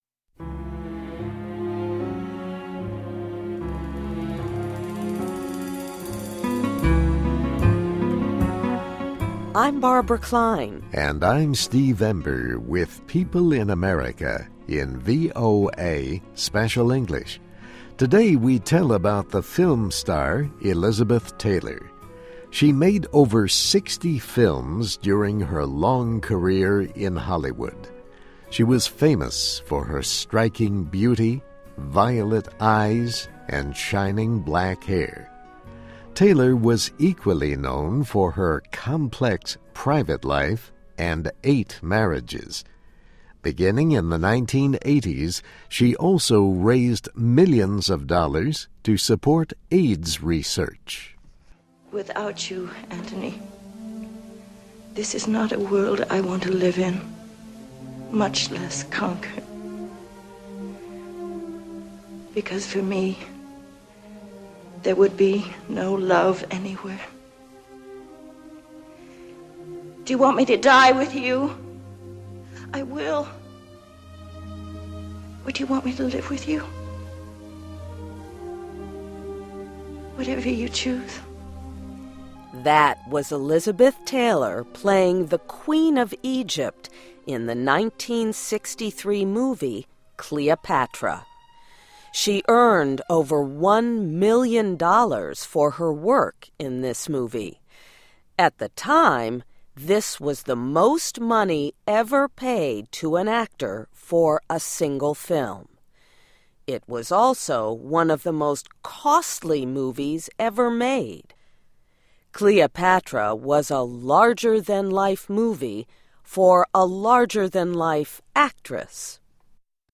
That was Elizabeth Taylor playing the queen of Egypt in the nineteen sixty-three movie ��Cleopatra.�� She earned over one million dollars for her work in this movie.